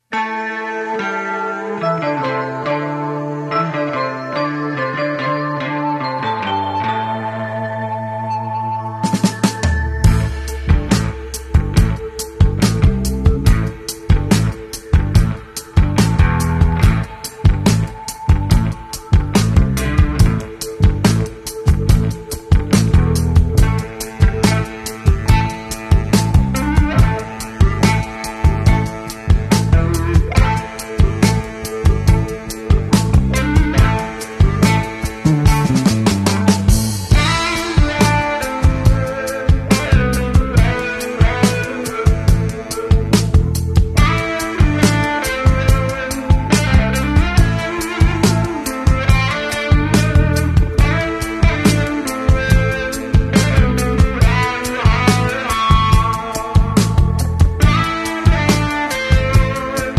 Long instrumental psych/r&b thing.